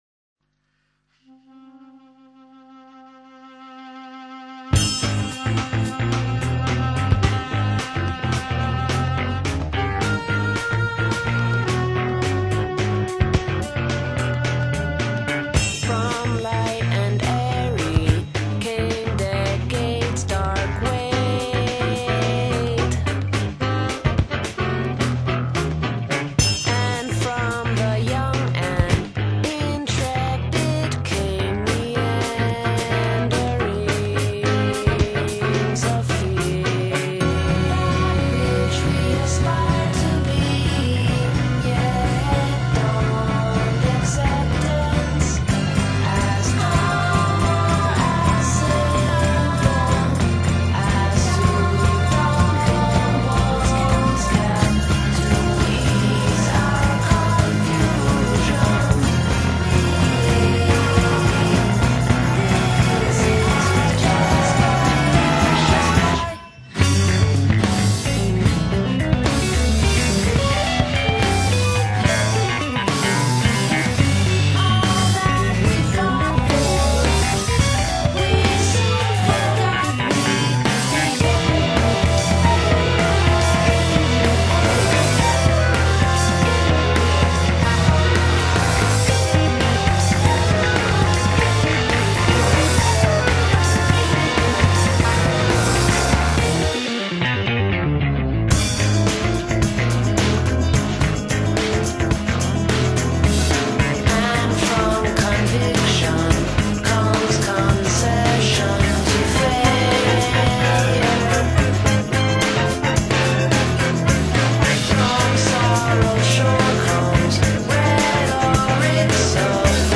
guitars
saxes and clarinet
bass
voice and keys
drums